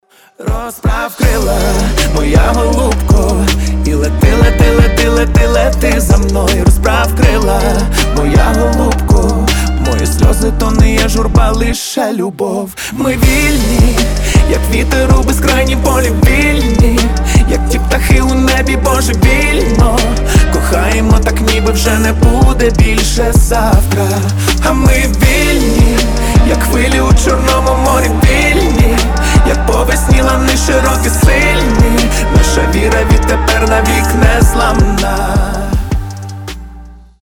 • Качество: 320, Stereo
сильные
вдохновляющие
воодушевляющие